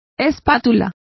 Complete with pronunciation of the translation of spatula.